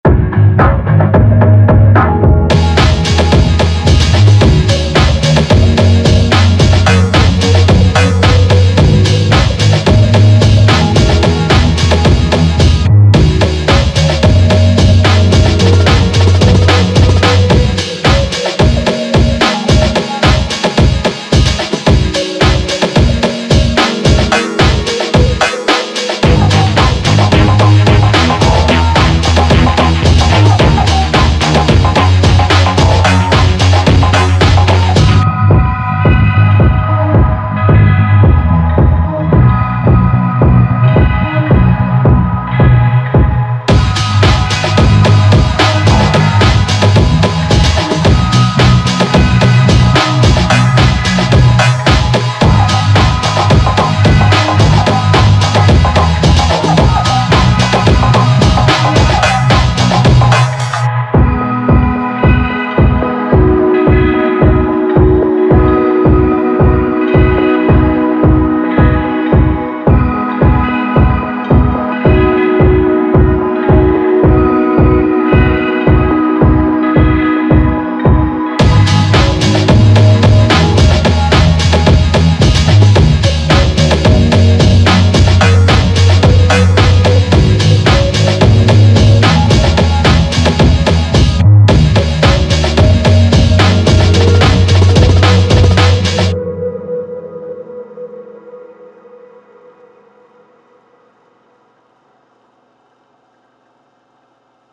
Synths are Zebra 3 and Pigments. Drums are a combo of Live kits and 2 sliced/resampled drum loops from Splice.